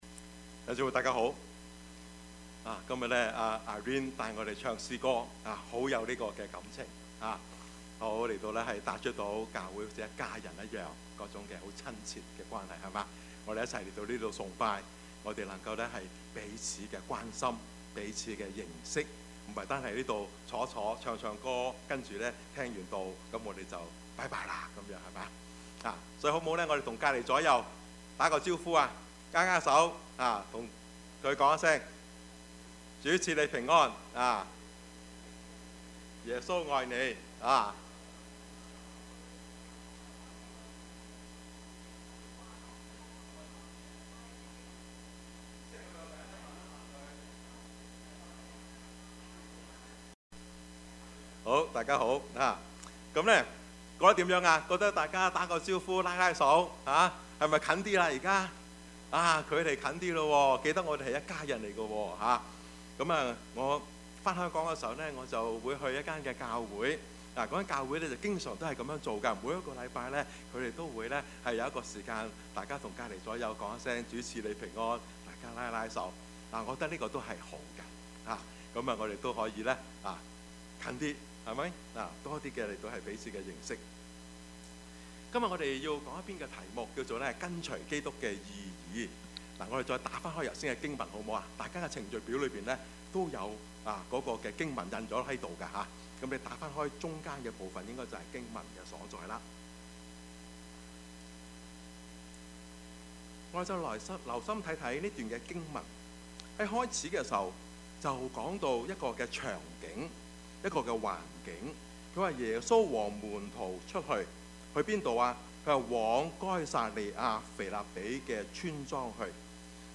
Service Type: 主日崇拜
Topics: 主日證道 « 愛子與石頭 我是誰？